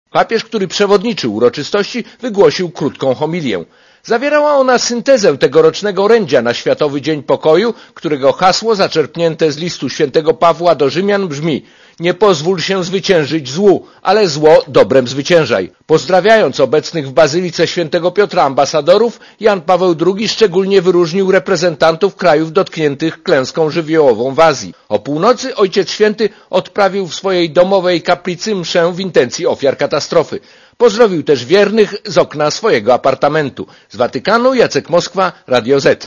Korespondencja z Rzymu